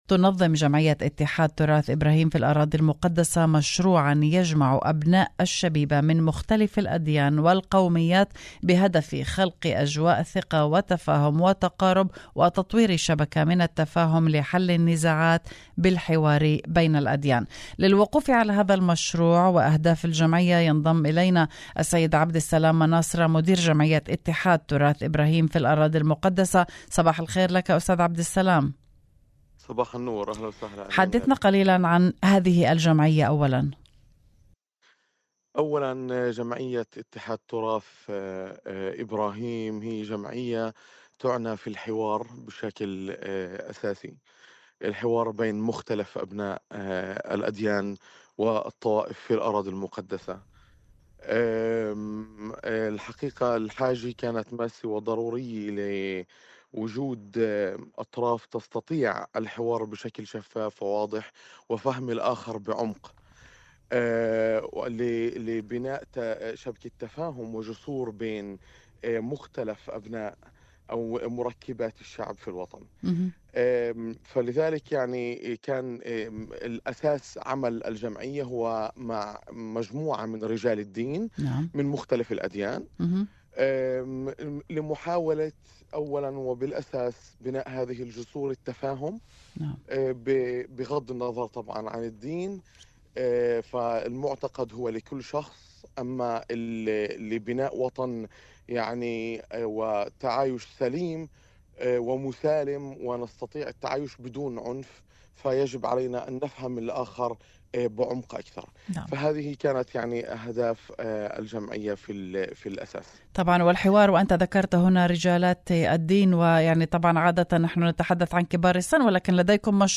Interview Text Translation: